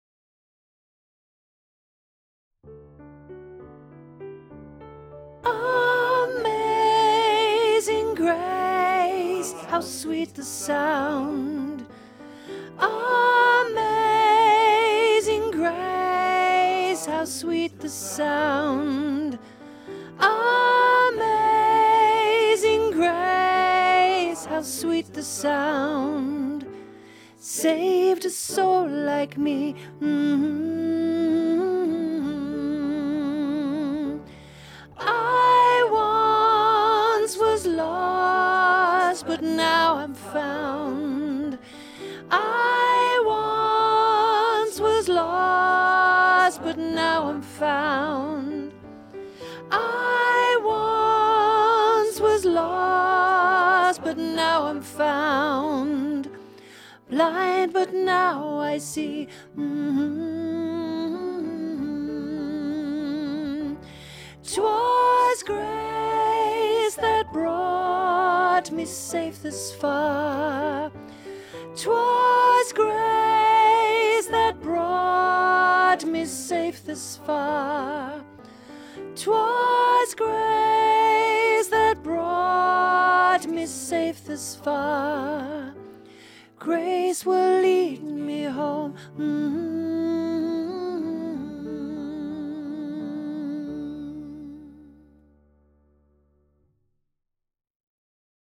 22 Amazing grace (Soprano learning track)
Genre: Choral.